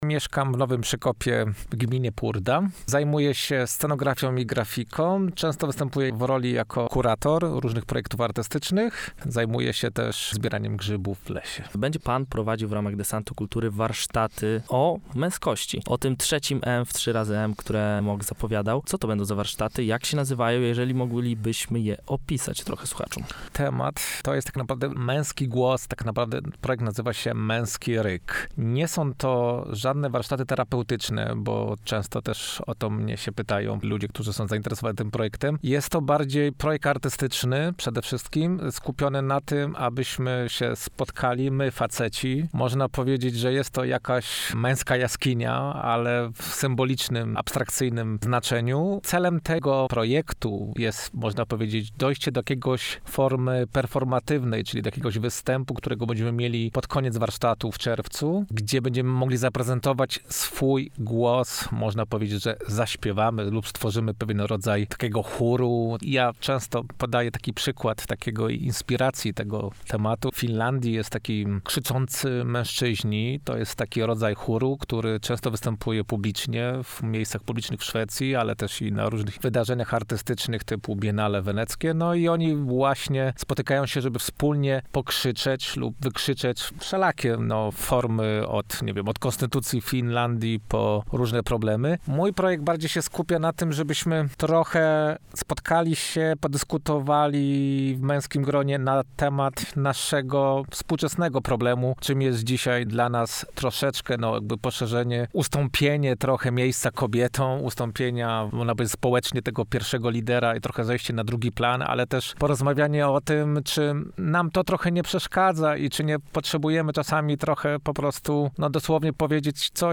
O tym, na czym chcą się skupić, opowiedzieli także w studiu Radia UWM FM.